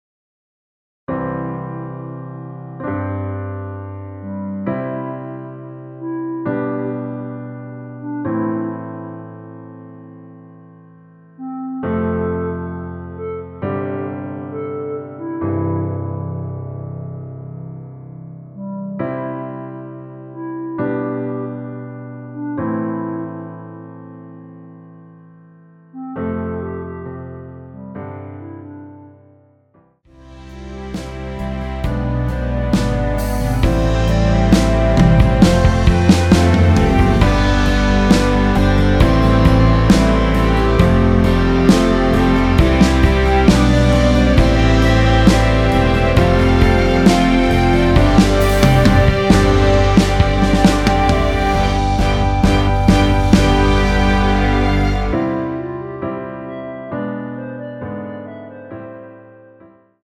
전주없이 시작하는 곡이라 1마디 전주 만들어 놓았습니다.(미리듣기 참조)
원키에서(-1)내린 멜로디 포함된 MR입니다.(미리듣기 확인)
앞부분30초, 뒷부분30초씩 편집해서 올려 드리고 있습니다.
(멜로디 MR)은 가이드 멜로디가 포함된 MR 입니다.